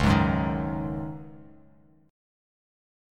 C#sus2sus4 chord